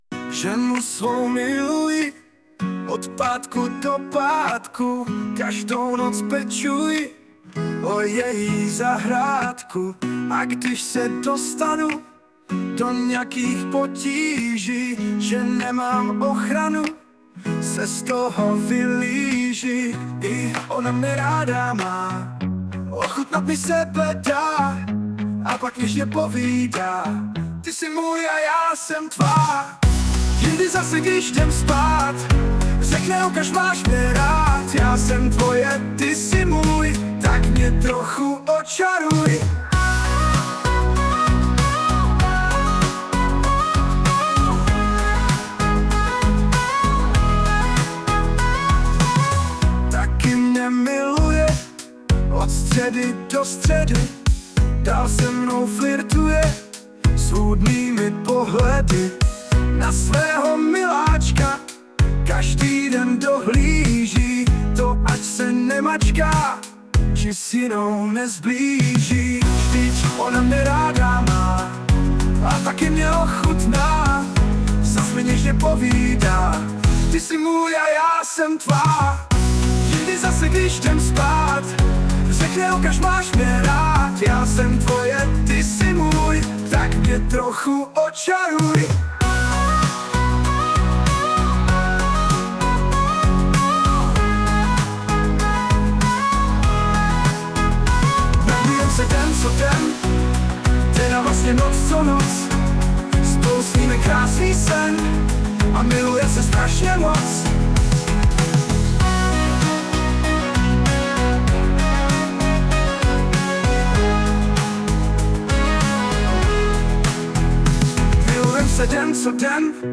Anotace: Zhudebněno pomoci AI.